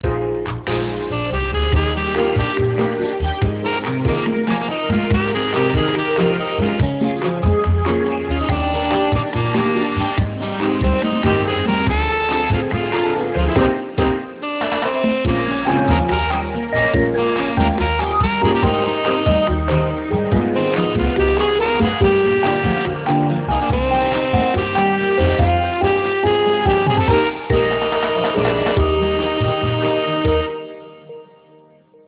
HoldingMessage1.amr